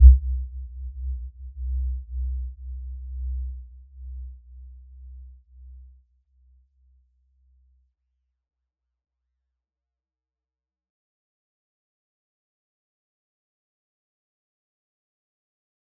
Warm-Bounce-C2-f.wav